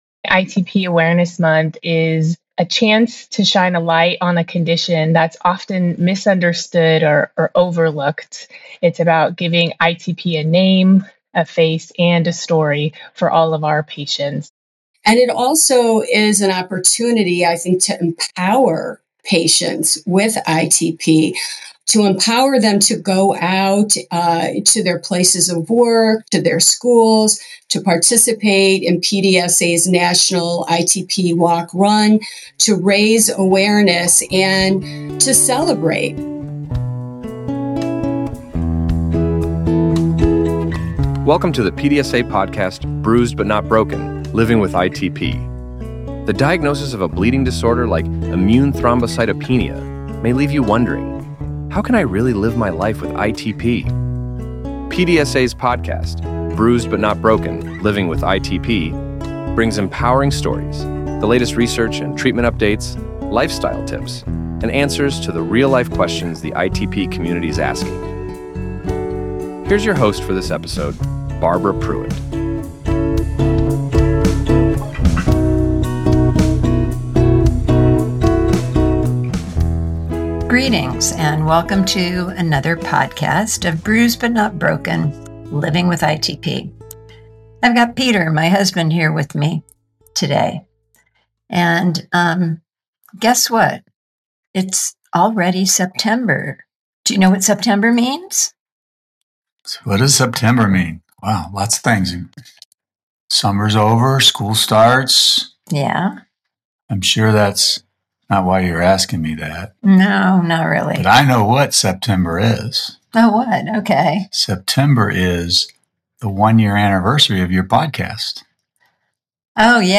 You’ll hear how awareness campaigns began, what’s planned globally this year, and simple ways anyone can get involved—from wearing purple to hosting creative fundraisers. This uplifting conversation highlights the power of awareness, community, and connection in turning a rare disease into a recognized cause.